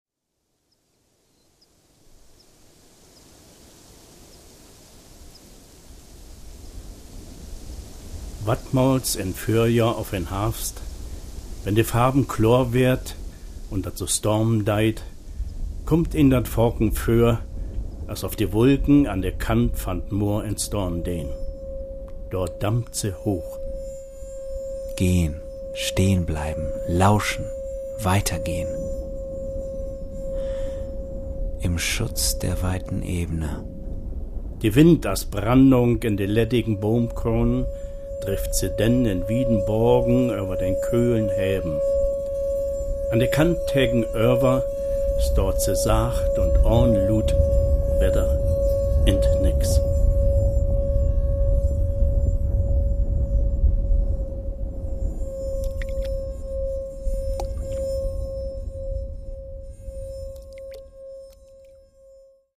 Akustische Rauminstallationen im Oldenburger Schlossfür ein Projekt des Staatstheaters Oldenburg.